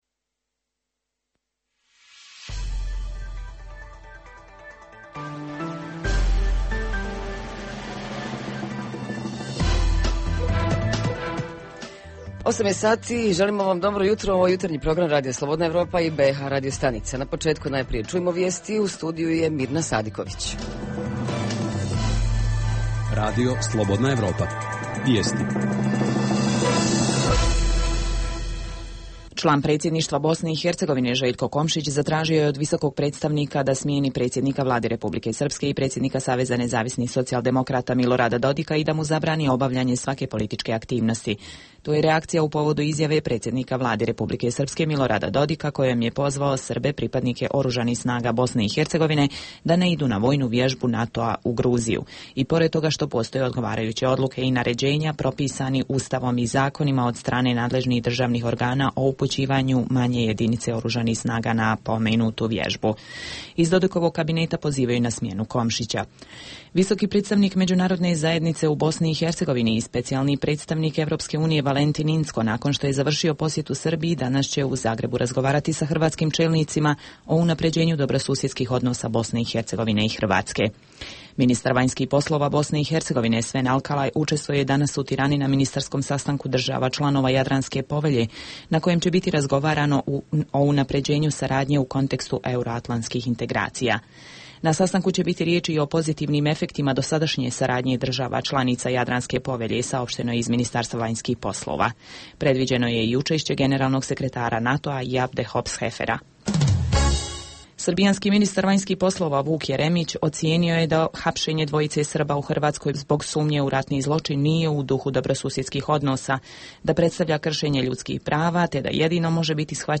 Jutarnji program za BiH koji se emituje uživo. 8. maj se obilježava kao Međunarodni dan Crvenog krsta/križa, pa je to i tema našeg programa. Reporteri iz cijele BiH javljaju o najaktuelnijim događajima u njihovim sredinama.
Redovni sadržaji jutarnjeg programa za BiH su i vijesti i muzika.